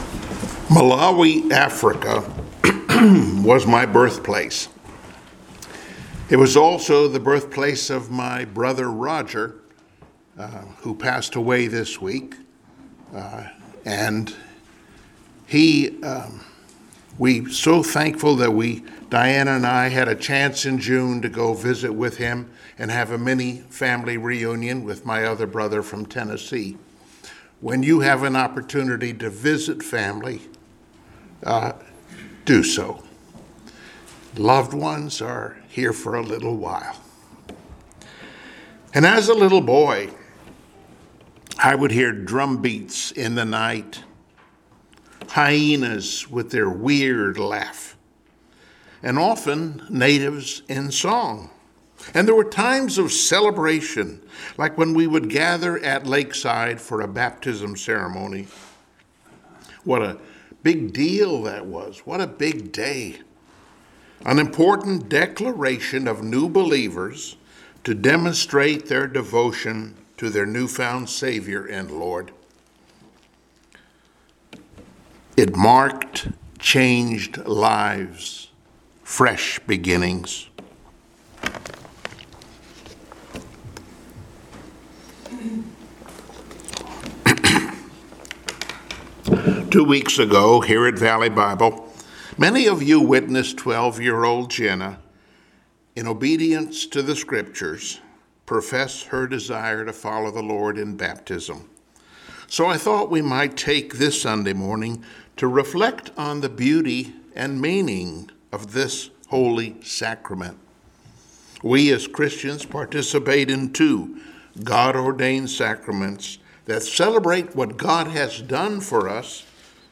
Passage: Romans 6:3-5 Service Type: Sunday Morning Worship Topics